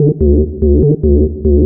BL 145-BPM 2-D.wav